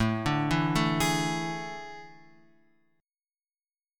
A Major 7th Suspended 4th